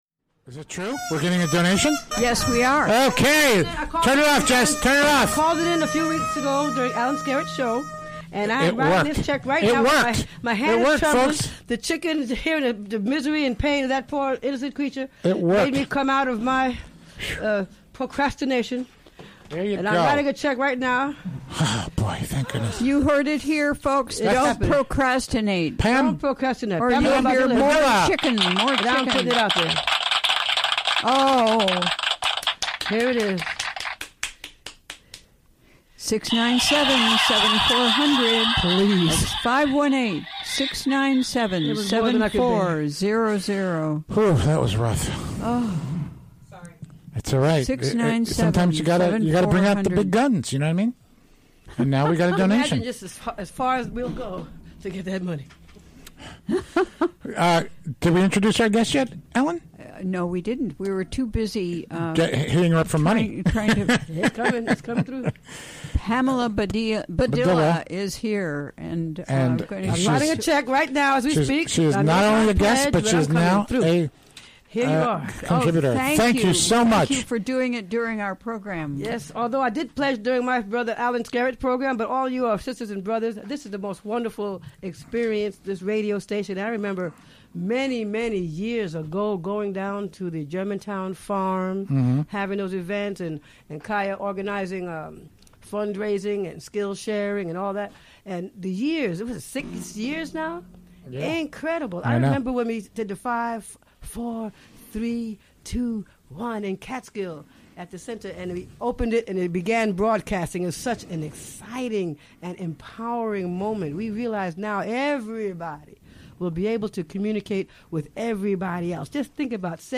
Recorded during the WGXC Afternoon Show Thursday, March 9, 2017.